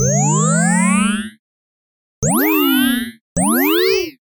04977 analog game teleport dings
analog bleep commnication dings game oldschool scoring teleport sound effect free sound royalty free Sound Effects